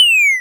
fall1.wav